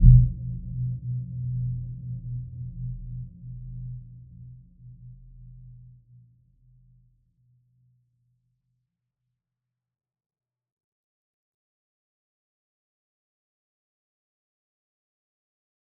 Dark-Soft-Impact-B2-f.wav